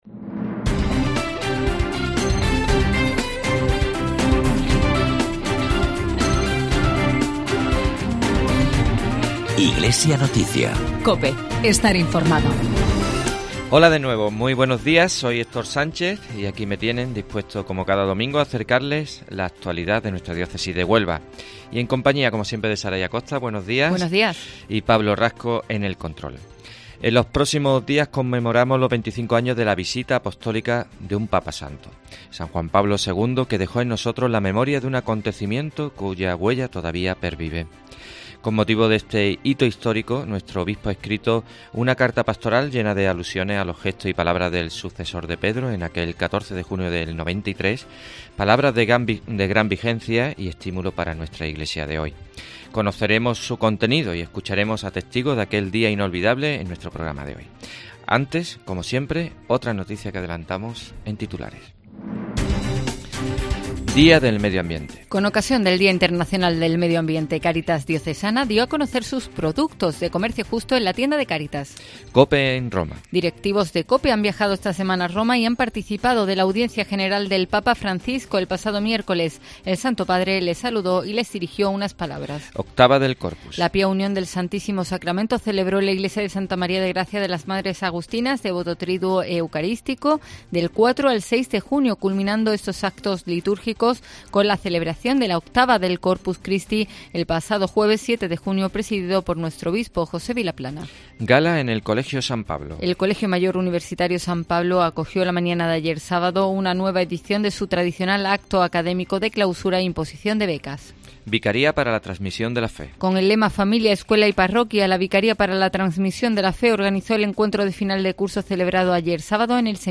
Conoceremos su contenido y escucharemos a testigos de aquel día inolvidable en nuestro programa de esta semana.